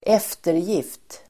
Uttal: [²'ef:terjif:t]